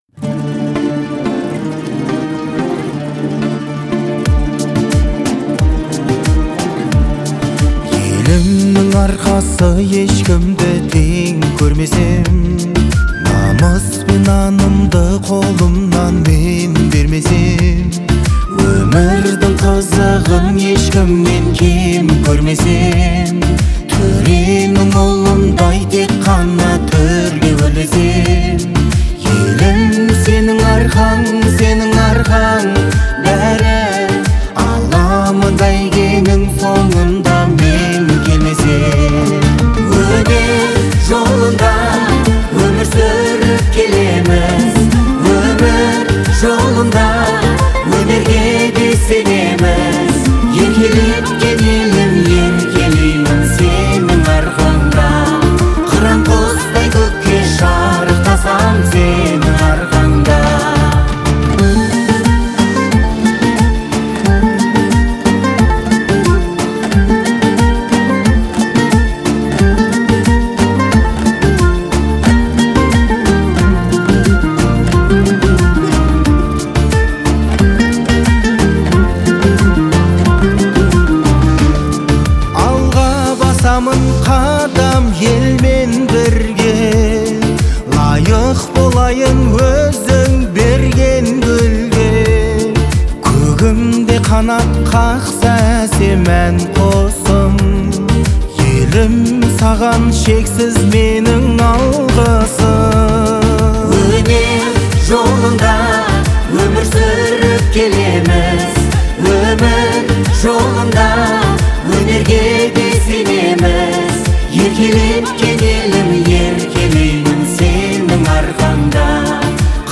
это трогательная композиция в жанре казахской поп-музыки.